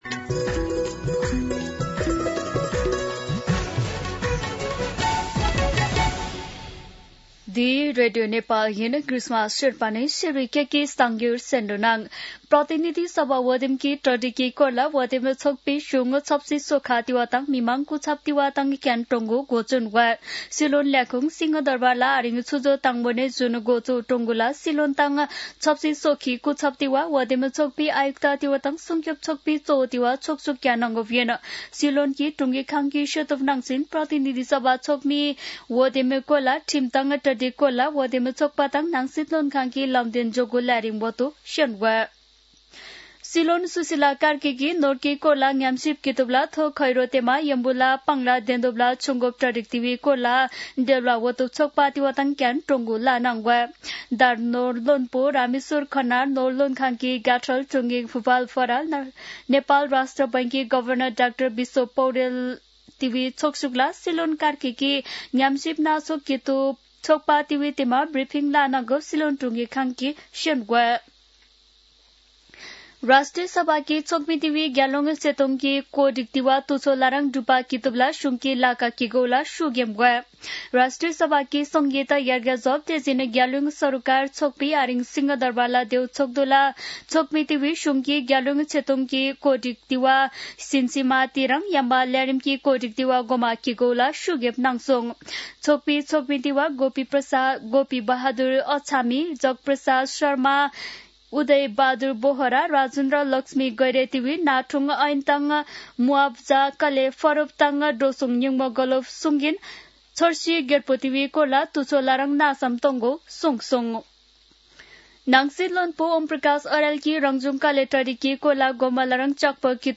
शेर्पा भाषाको समाचार : ७ पुष , २०८२
Sherpa-News-07.mp3